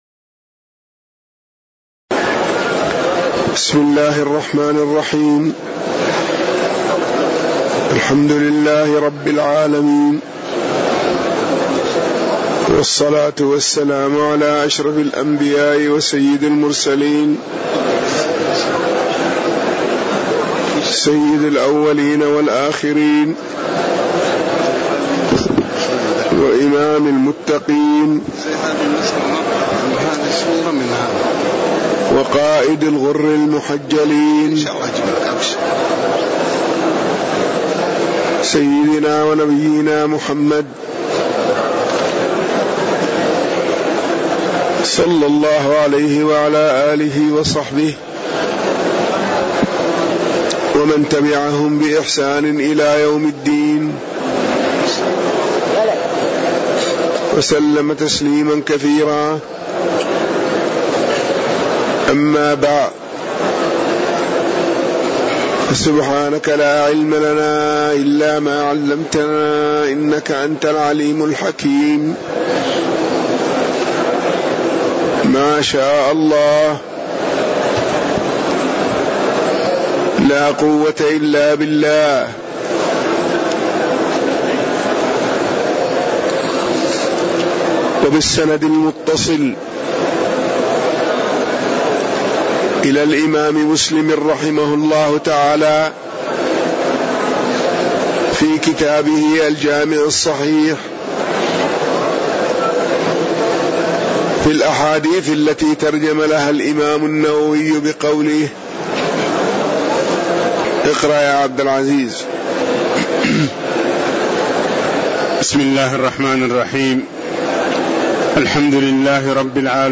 تاريخ النشر ١٤ جمادى الأولى ١٤٣٧ هـ المكان: المسجد النبوي الشيخ